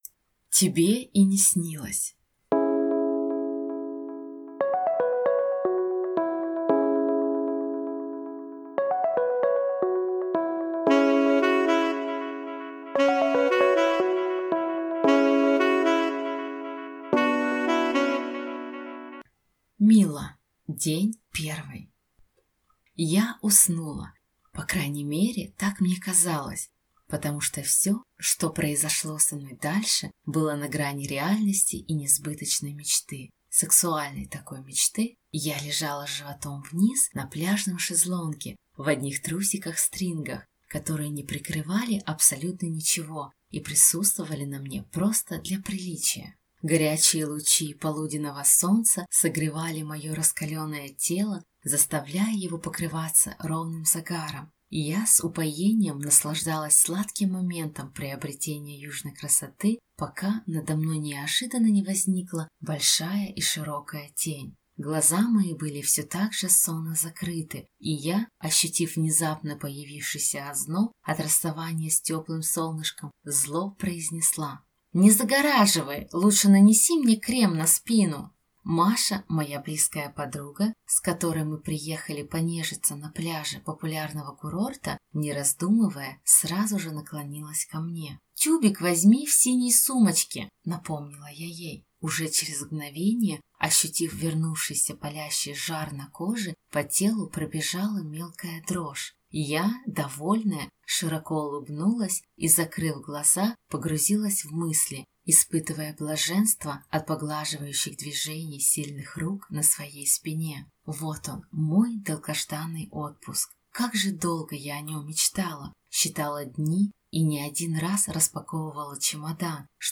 Аудиокнига Тебе и не снилось | Библиотека аудиокниг
Прослушать и бесплатно скачать фрагмент аудиокниги